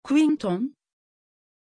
Pronunciation of Quinton
pronunciation-quinton-tr.mp3